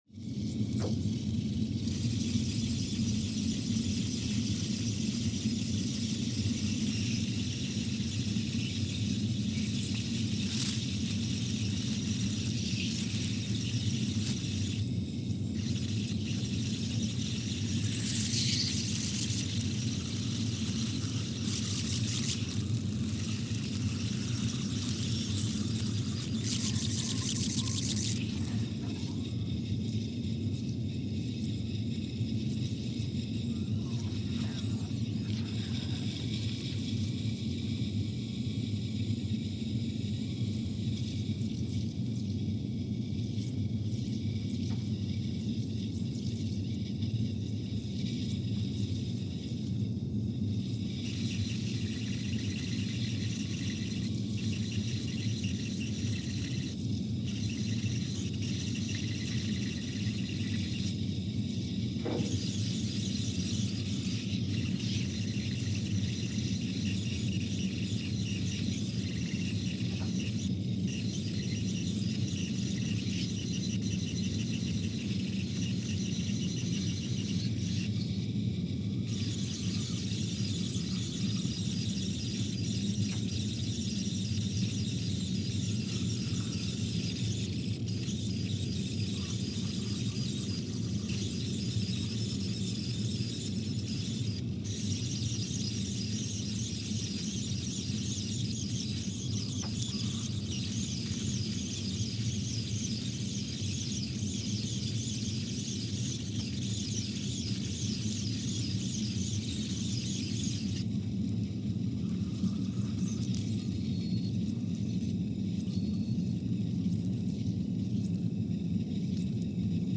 Scott Base, Antarctica (seismic) archived on November 22, 2019
No events.
Station : SBA (network: IRIS/USGS) at Scott Base, Antarctica
Sensor : CMG3-T
Speedup : ×500 (transposed up about 9 octaves)
Loop duration (audio) : 05:45 (stereo)